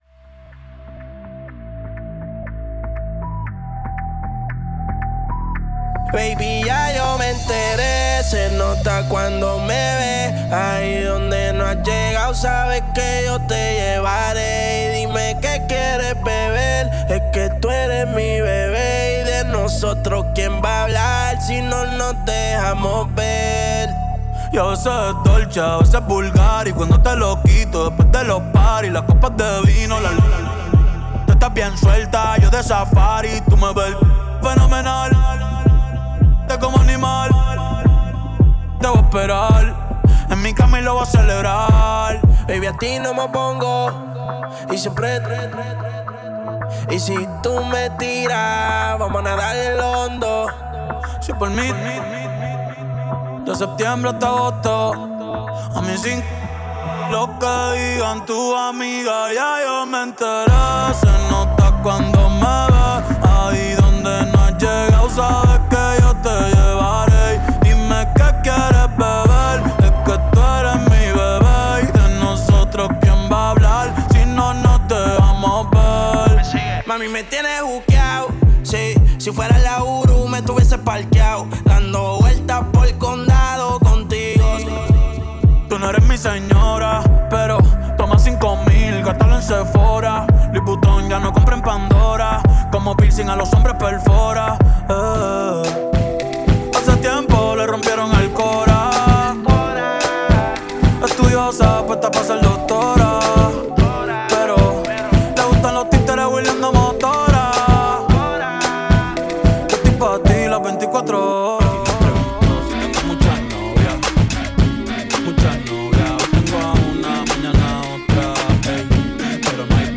A live downtempo, Latin, and Top 40 dance set